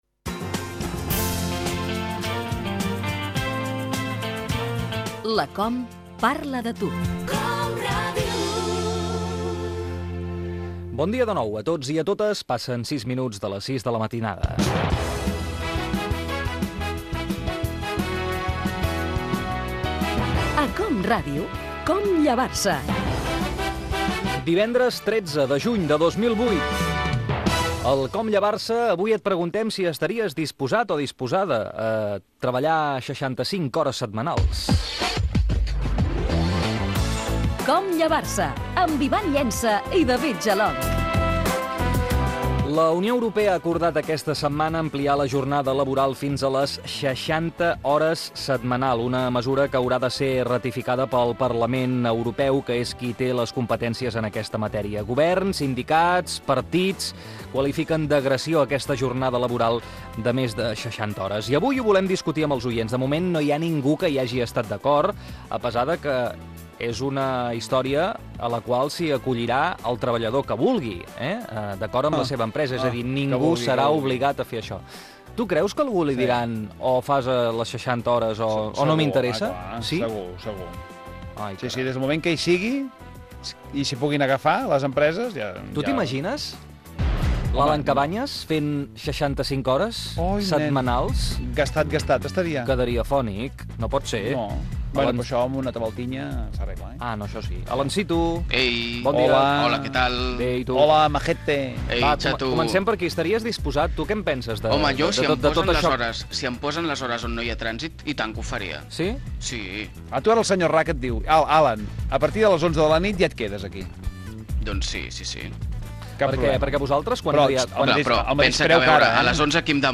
Entreteniment
Fragment extret de l'arxiu sonor de COM Ràdio